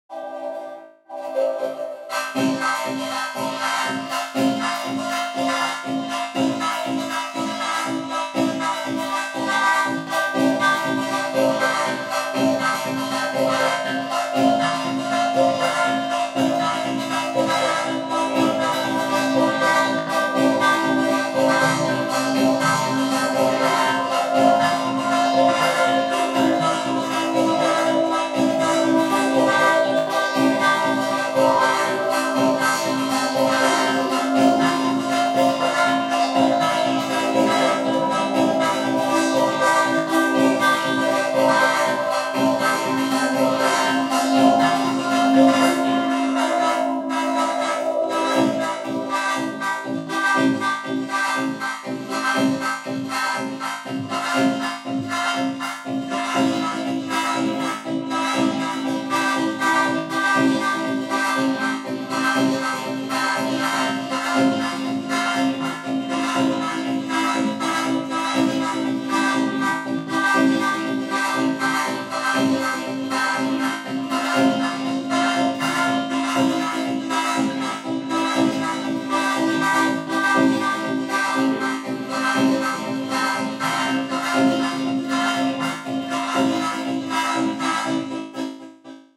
bruh sound